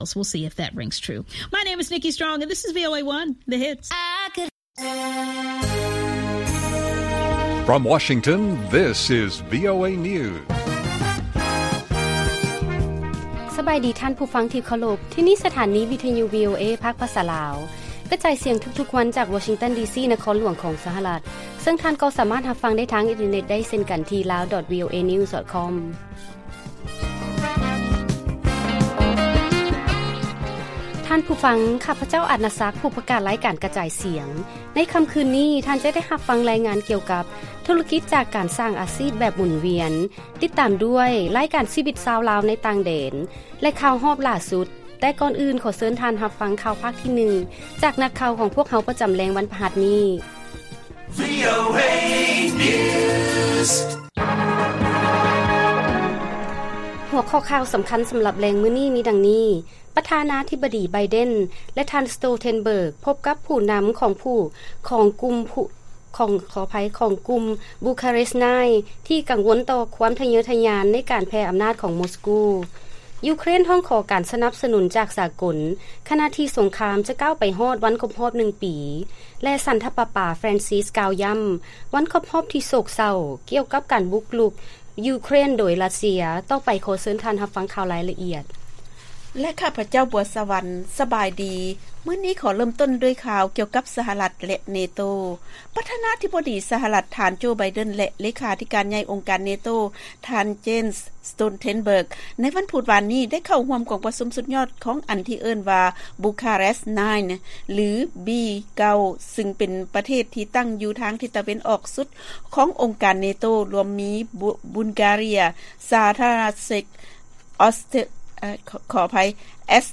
ລາຍການກະຈາຍສຽງຂອງວີໂອເອ ລາວ: ປ. ໄບເດັນ ແລະ ທ. ສໂຕເທັນເບີກພົບກັບຜູ້ນຳ ຂອງກຸ່ມບູຄາແຣສ ໄນນ໌ ທີ່ກັງວົນຕໍ່ຄວາມທະເຍີທະຍານໃນການແຜ່ອຳນາດຂອງມົສກູ